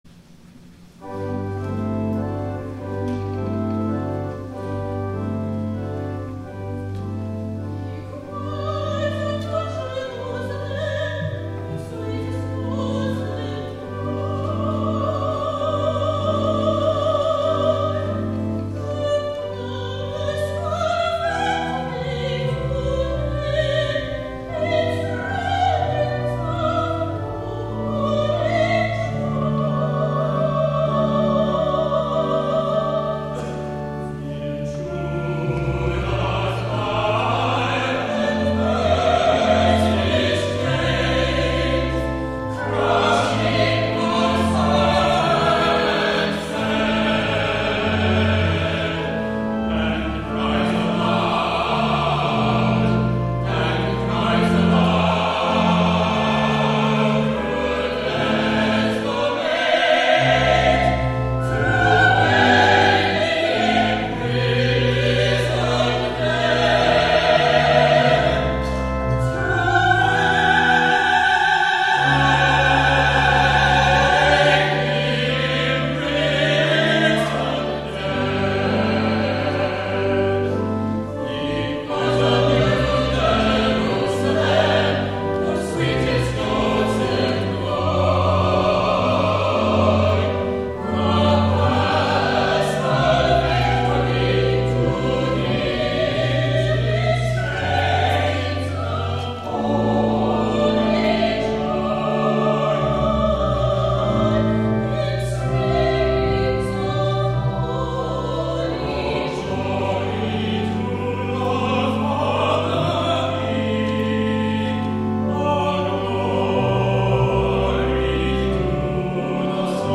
Easter Sunday
THE ANTHEM
(8:30 a.m. worship)